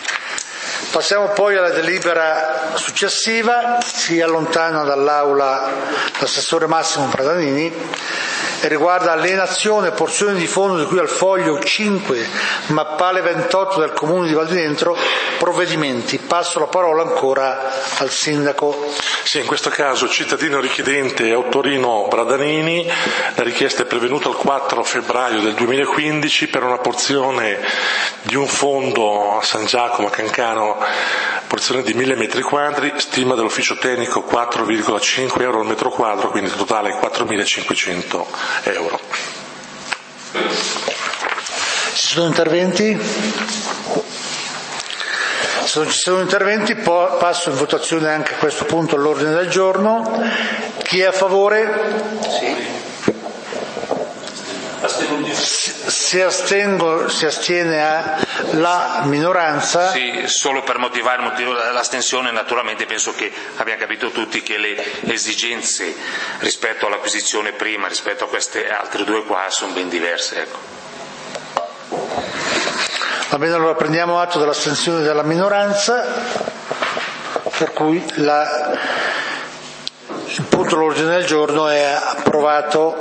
Consiglio comunale di Valdidentro del 23 Aprile 2015